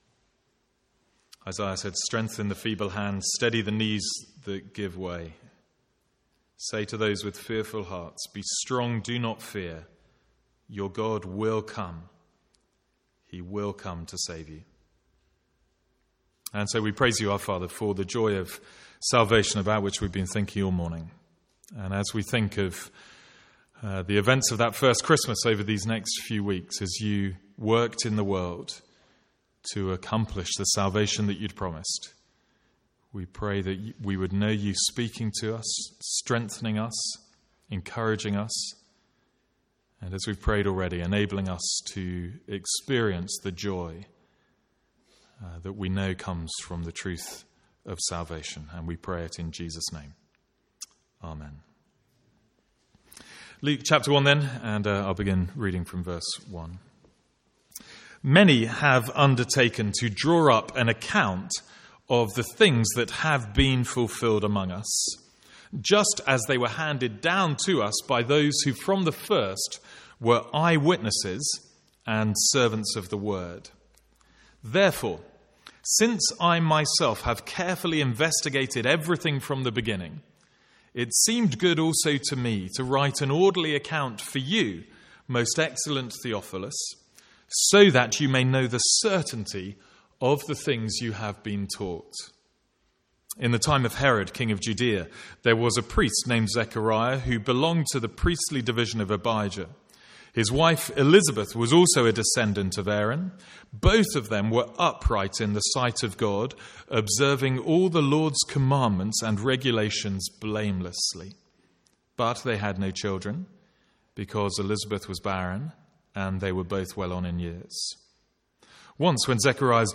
From the Sunday morning series in Luke.
Download Download Reference Our sermon series in Luke 1-2 from 2015.